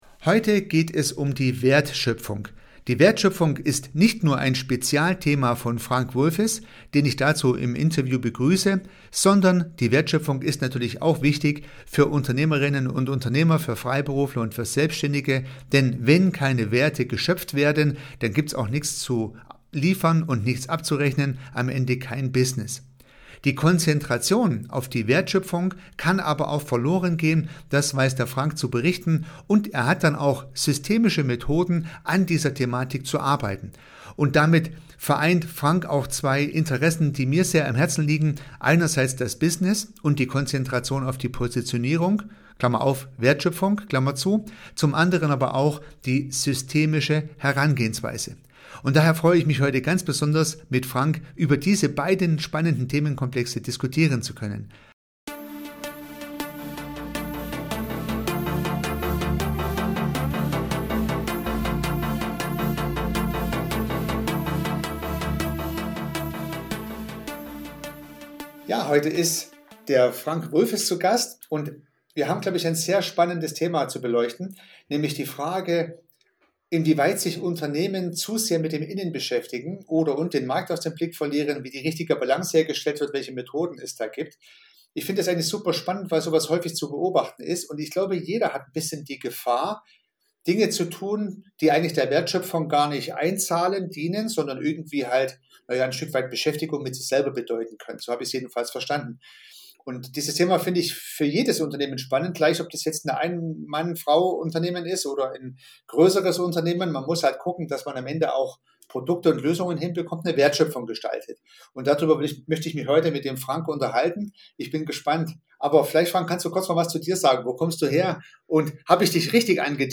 P006 WERTSCHÖPFUNG - so konzentrierst Du Dich auf das Wesentliche, das Interview